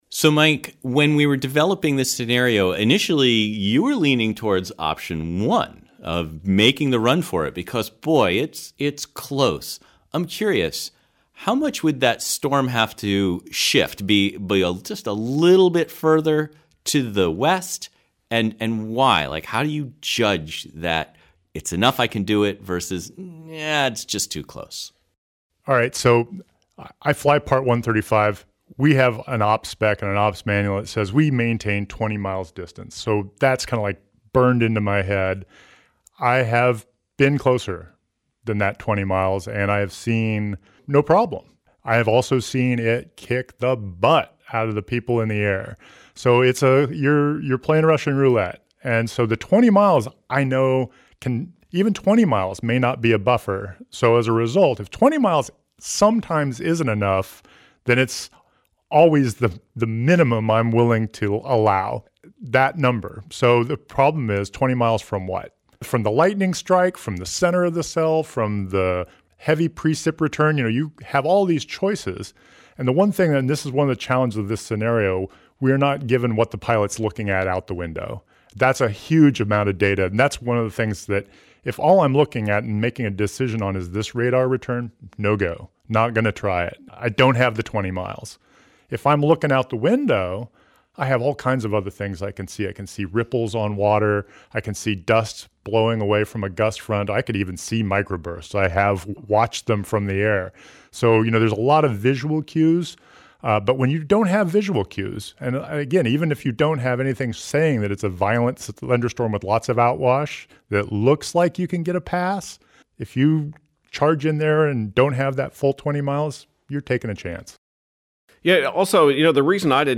Split_Decision_at_Salt_Lake_roundtable.mp3